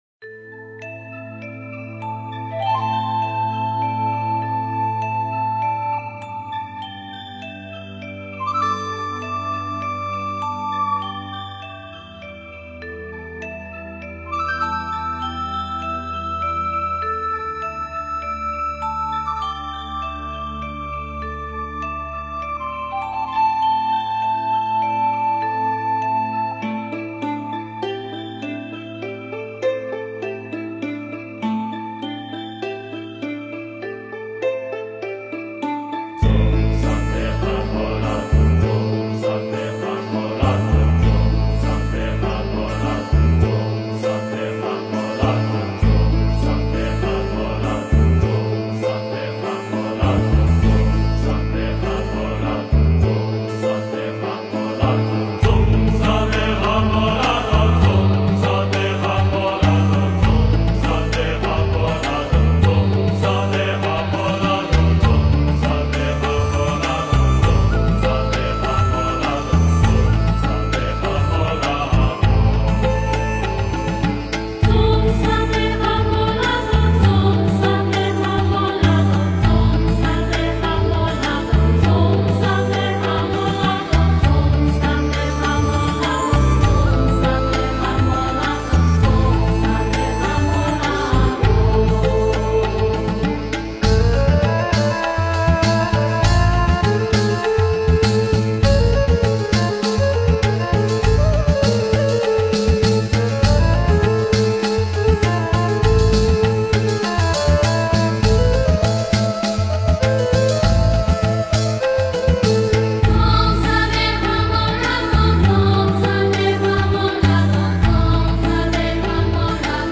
佛音 诵经 佛教音乐 返回列表 上一篇： 莲华生大士咒 下一篇： 弥勒颂 相关文章 加持咒--未知 加持咒--未知...